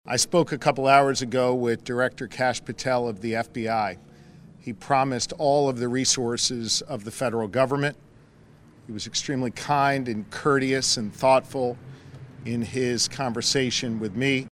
GOVERNOR SHAPIRO HAD THIS TO SAY…